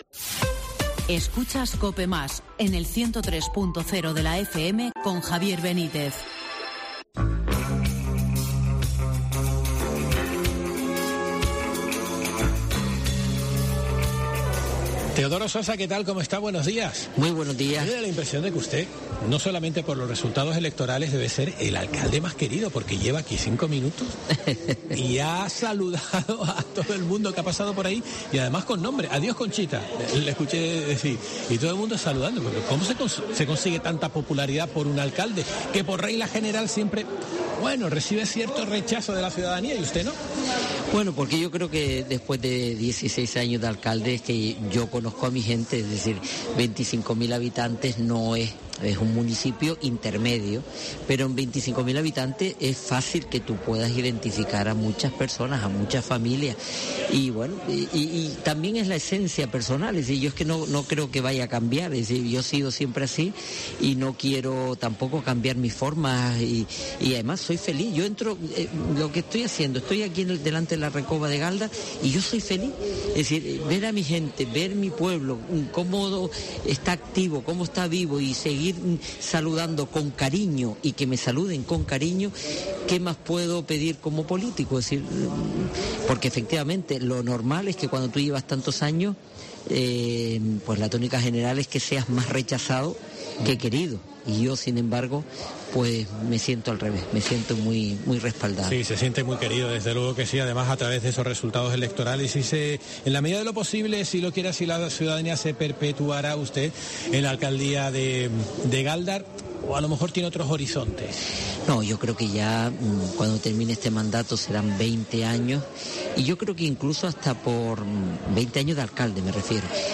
Mercado de La Recova en Gáldar